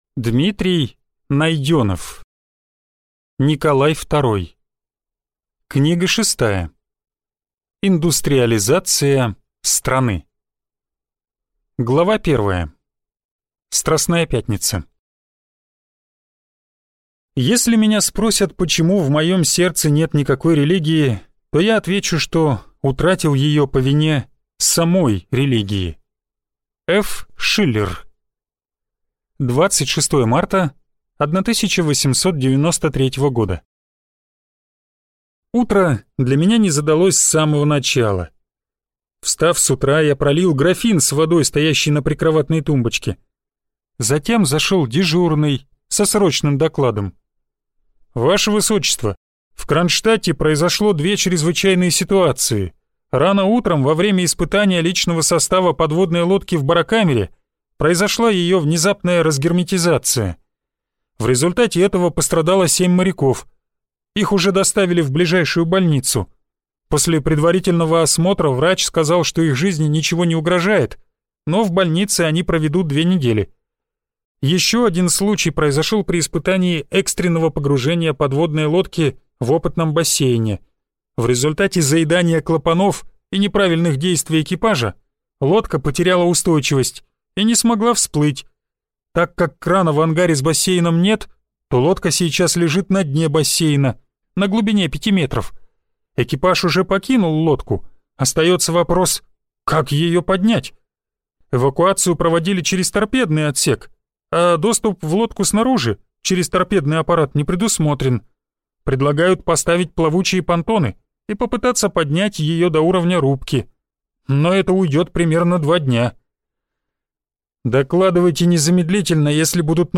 Аудиокнига Николай Второй. Книга шестая. Индустриализация страны | Библиотека аудиокниг